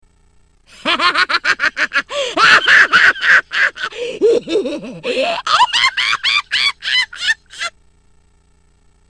Play, download and share Sachiko's Laugh original sound button!!!!
sachikos-laugh.mp3